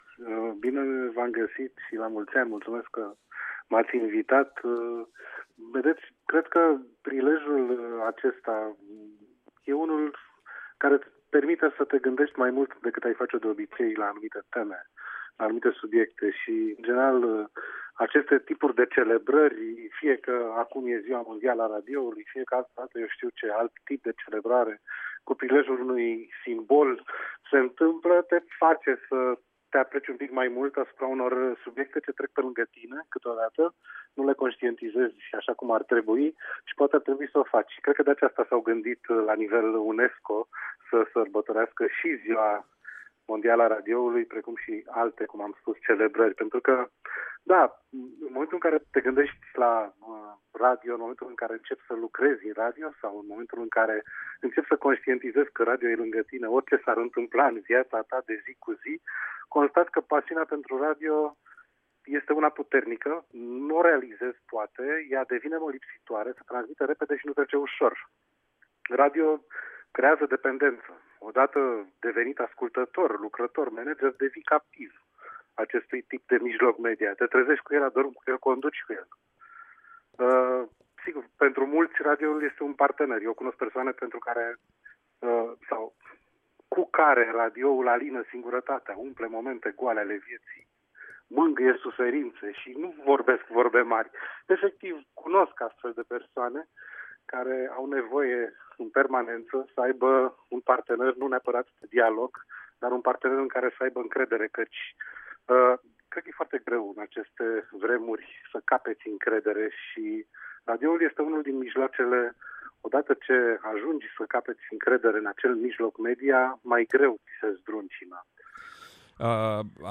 Invitat la Radio România Actualități, Preşedintele Director General al SRR, Răzvan Dincă, a transmis un mesaj tuturor celor ce iubesc radioul: